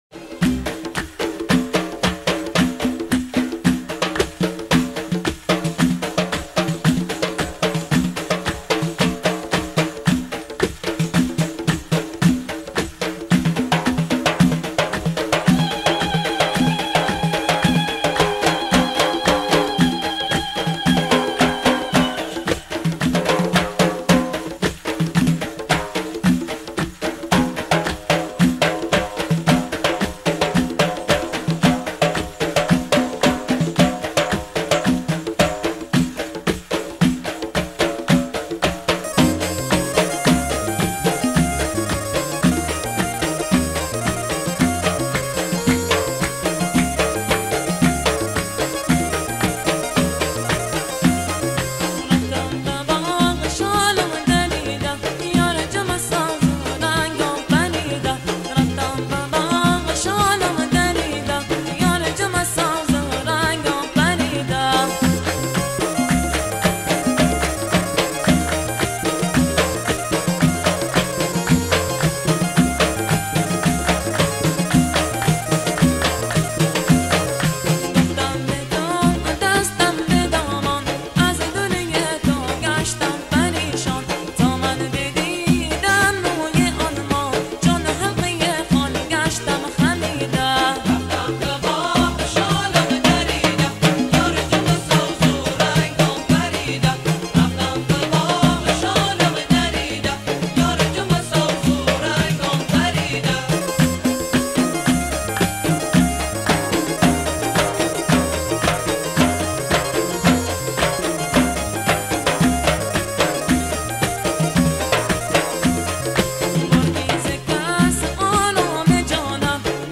اهنگ بندری